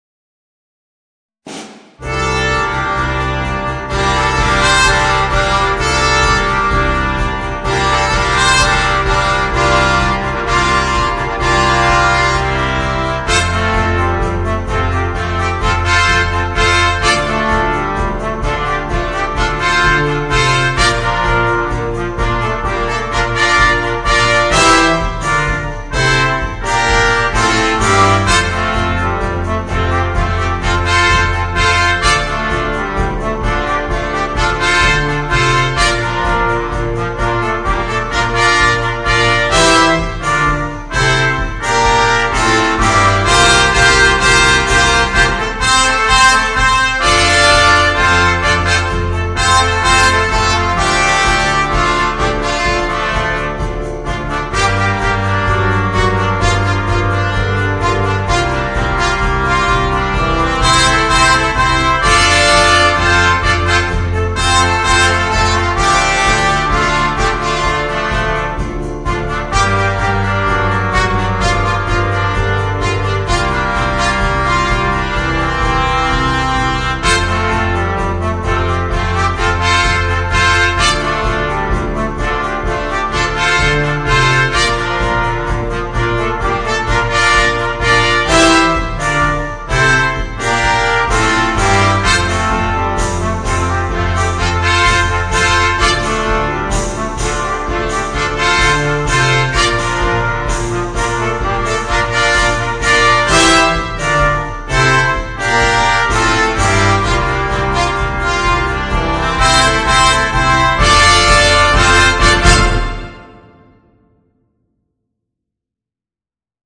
5-stimmiges Ensemble